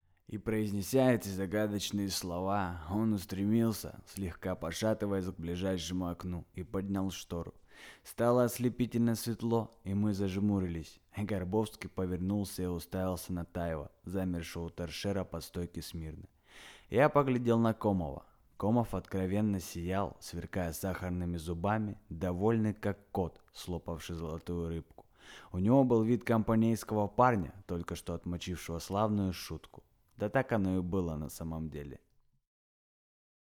Вариация №3 (просто чтение)